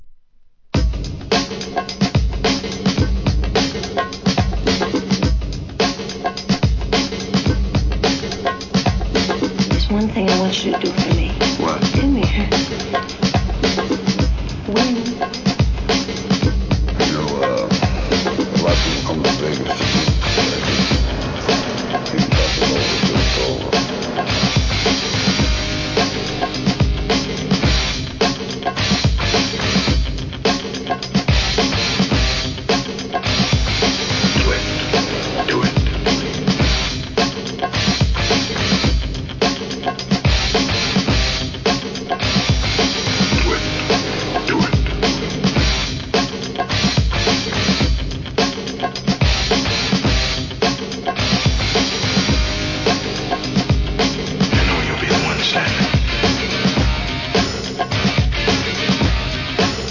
HIP HOP/R&B
有名映画音楽の数々をクラブユースにエディットする企画もの!!